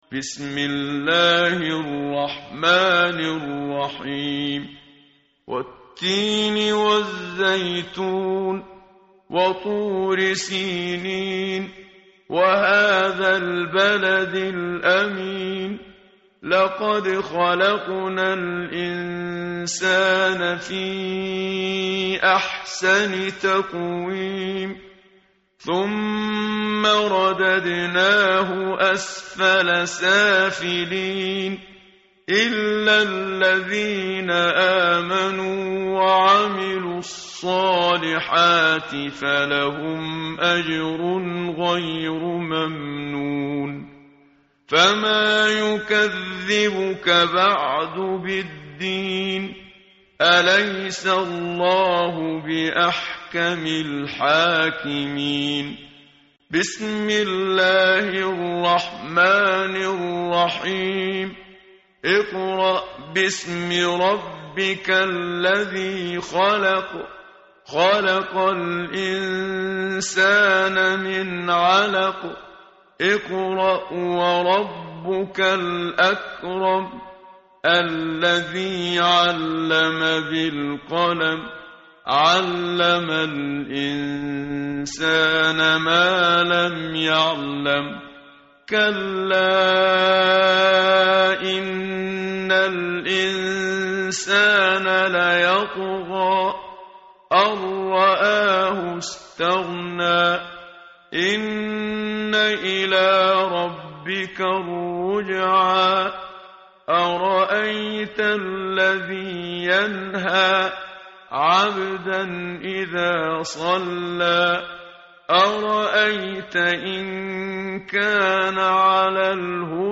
tartil_menshavi_page_597.mp3